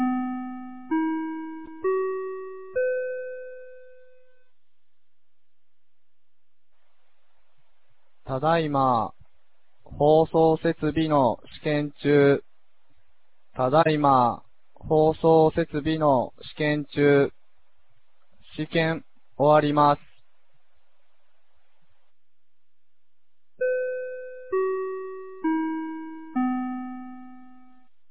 2019年08月10日 16時01分に、由良町より全地区へ放送がありました。